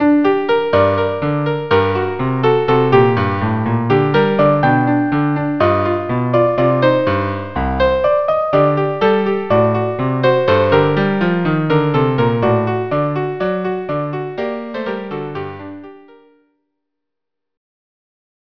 traditional Israeli song
Instrumentation: Violin; Violin 2/Viola; Cello